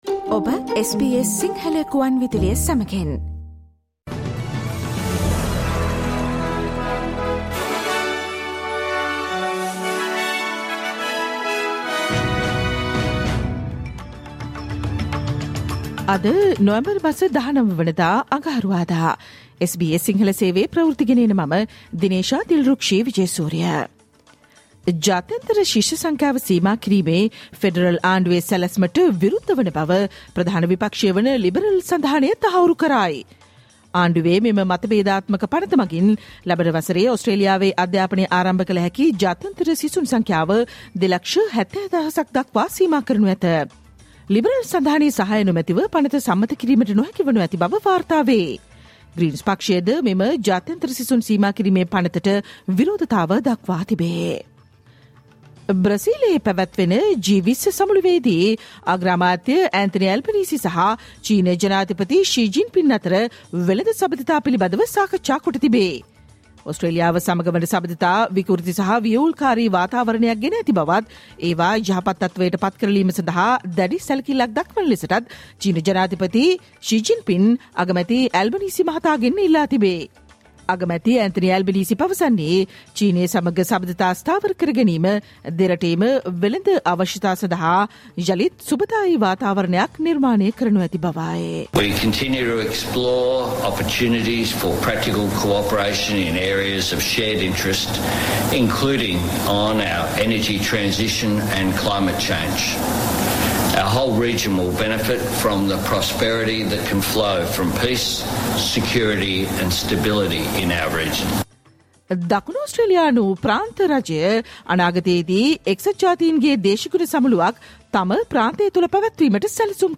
Australian news in Sinhala, foreign and sports news in brief.